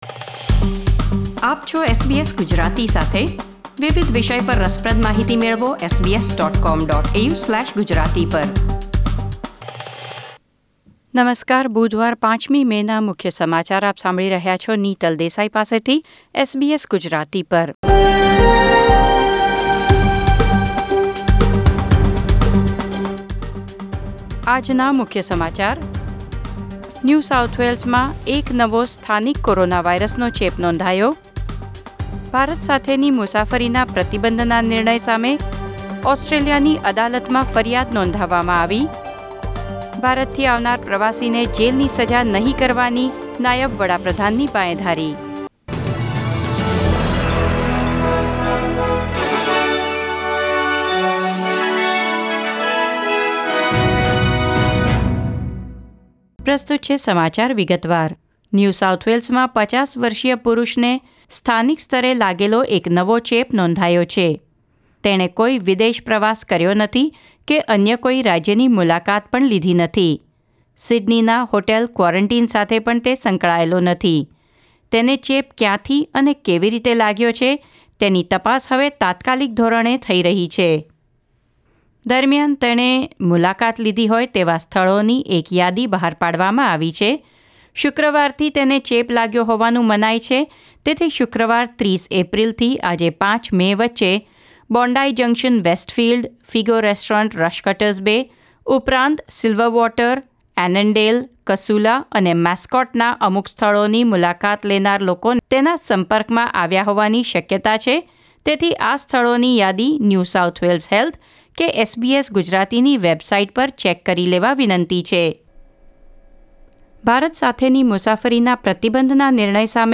SBS Gujarati News Bulletin 5 May 2021